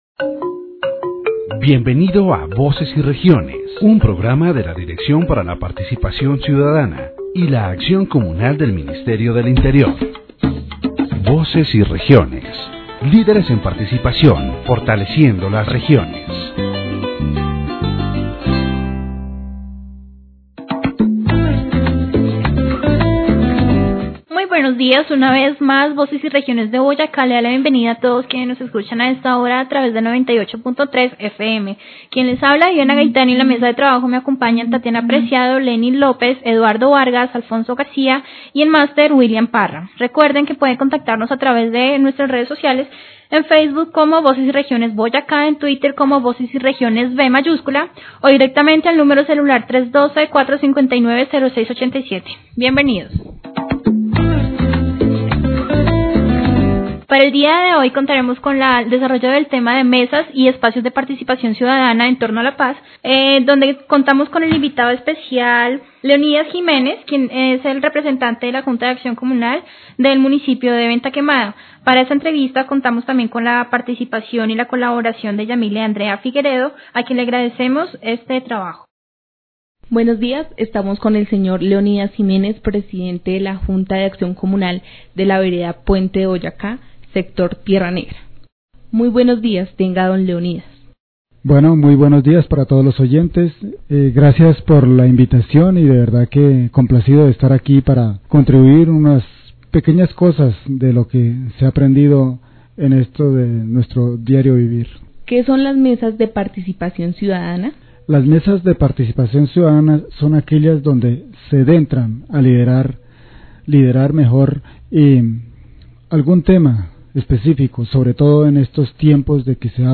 This radio program “Voces y Regiones” focuses on the roundtables and spaces for citizen participation around peace in Colombia.